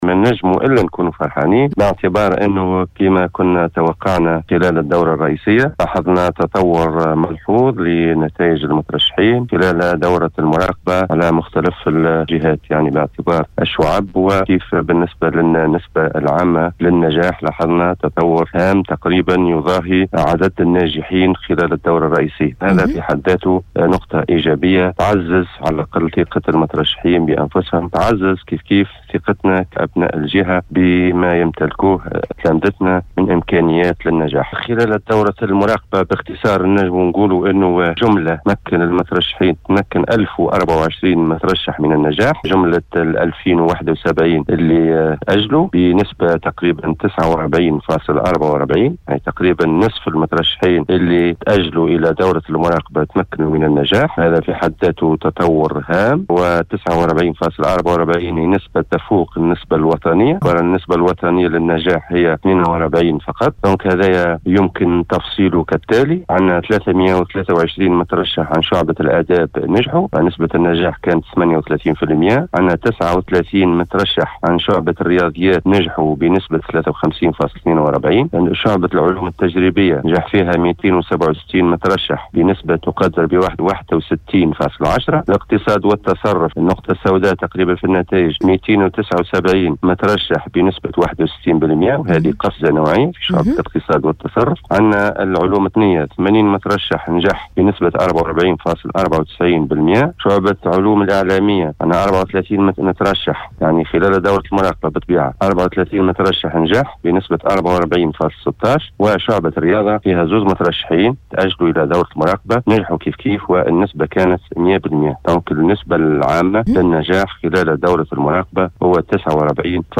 أفاد  المندوب الجهوي للتربية بالقصرين المنصف القاسمي ، خلال تدخله صباح اليوم الأحد 9 أوت 2020  ببرنامج بونجور ويكاند بإذاعة السيليوم أف أم ،  أنّ هناك تطوّر ملحوظ في نتائج المترشحين في دورة المراقبة على مختلف الجهات بآعتبار الشّعب و أيضا بآعتبار النسبة العامة للناجحين .